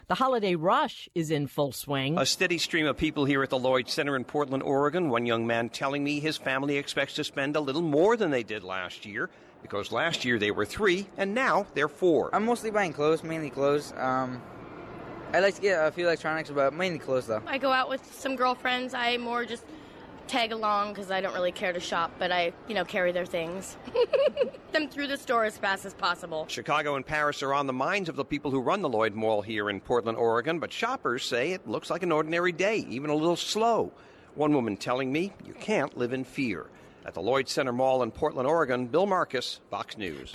LLOYD CENTER MALL PORTLAND OREGON BLACK FRIDAY